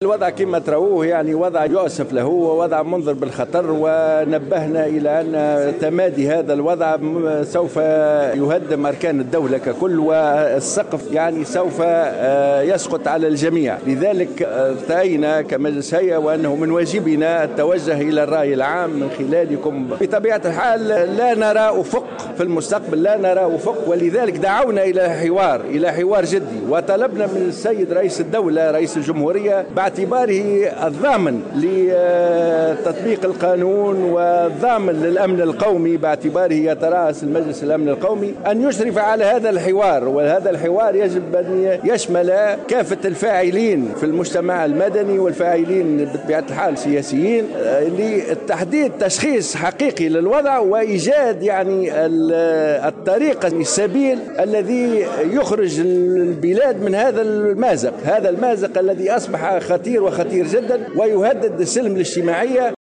وقال بودربالة، في تصريح لمراسلة الجوهرة أف أم، على هامش ندوة صحفية عقدتها اليوم الهيئة الوطنية للمحامين بتونس حول شلل المرفق القضائي وتداعياته على الوضع العام والحقوق والحريات بالبلاد، قال إنه على القضاة وكافة الأطراف مراعاة الوضع الصعب الذي تمر به البلاد و"النظر بعين الرحمة للدولة التي تمر بصعوبات كبيرة تستدعي مساندتها بدل تقديم مطالب مجحفة غير قادرة على تنفيذها"، وفق تعبيره.